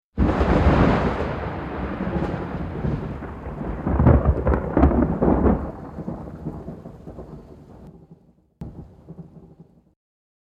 雷-2（164KB）